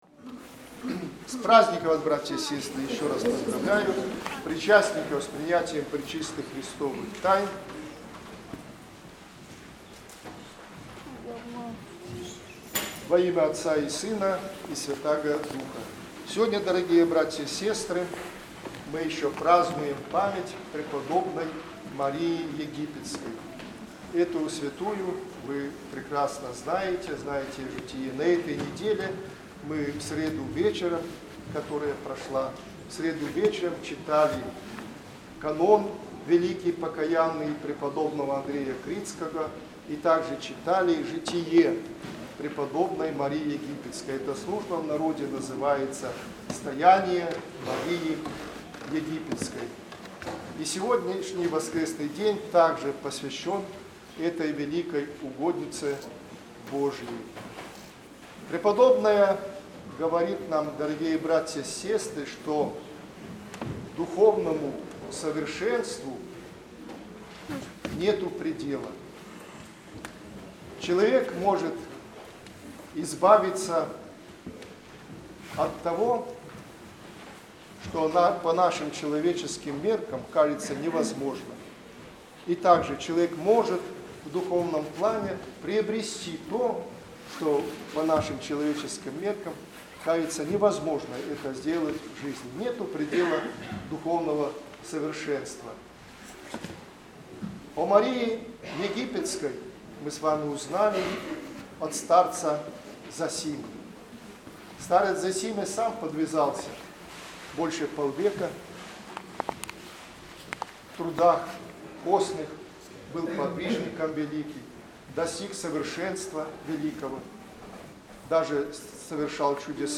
Проповедь